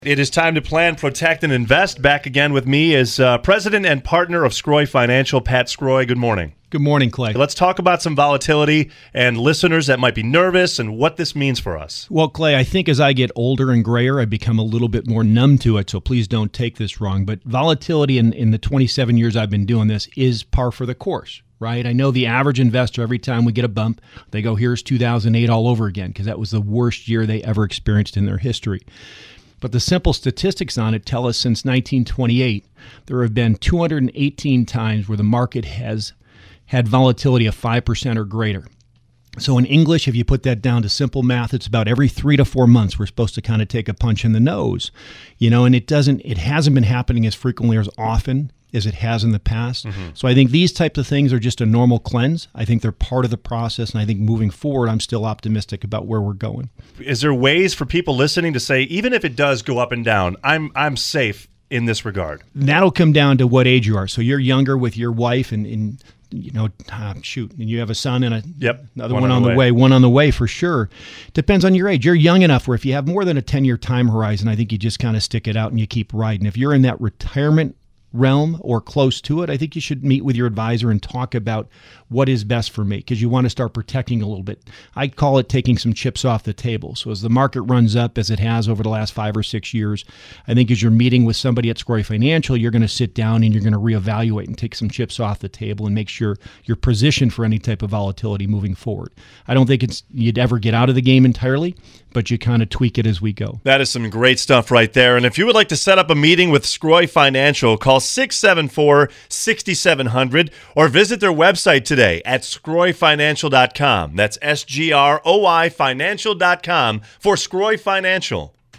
Plan.Protect.Invest. Episode #31 WEEKLY SEGMENT ON WYRK You can catch our weekly Plan.Protect.Invest. segment live on WYRK 106.5FM at 7:20am every Wednesday.